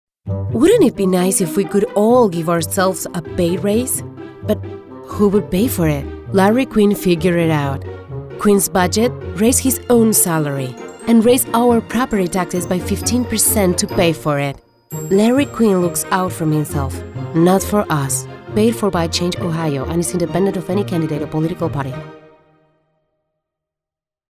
Female Spanish Republican Political Voiceover
English, w/SP accent